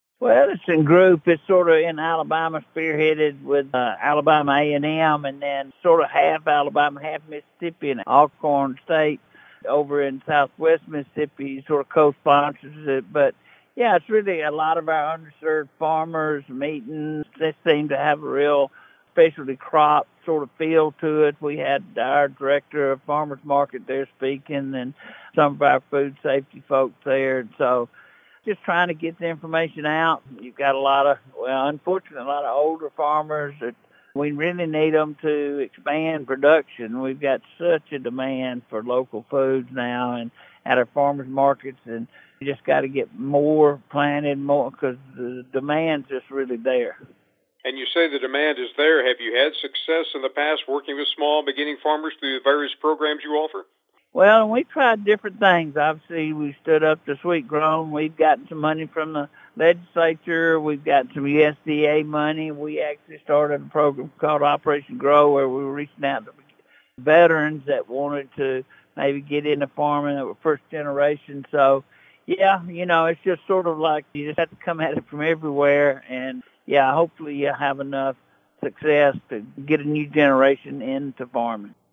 Commissioner, can you tell us more about the event?